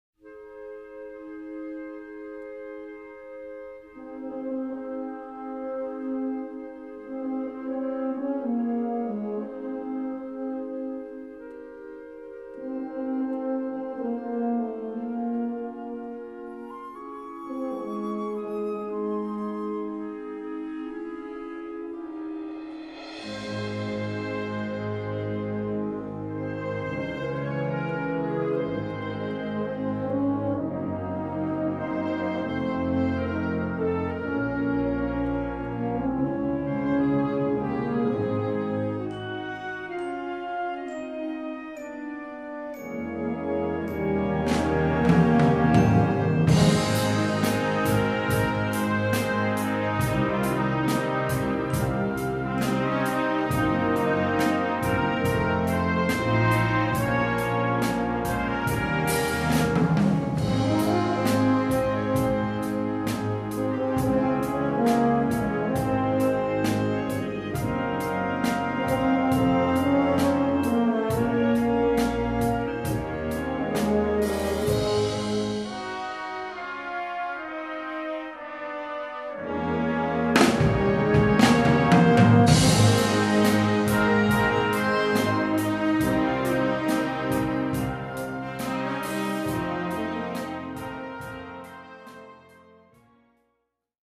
Recueil pour Harmonie/fanfare - Concert Band ou Harmonie